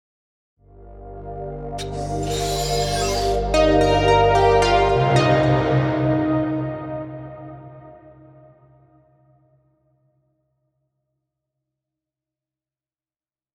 AUDIO LOGO